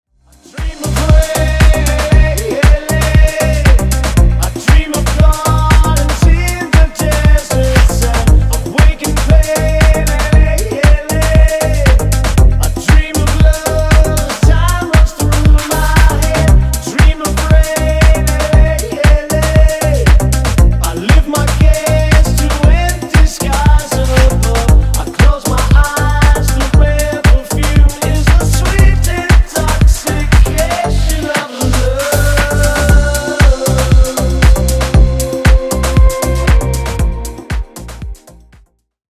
Ремикс # Поп Музыка
грустные